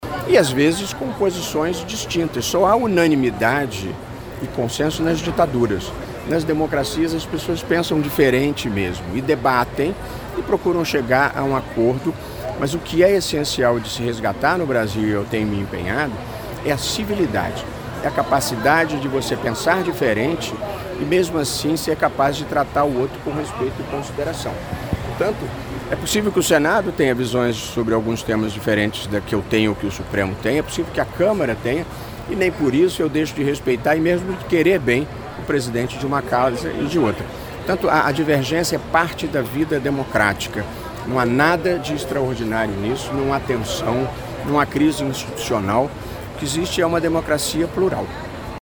Em entrevista ao programa Correio Debate, da Rádio Correio 98 FM, o magistrado defendeu que o debate seja de competência do Congresso Nacional, mas caso chegue ao âmbito da Suprema Corte, ele opinará a respeito do tema.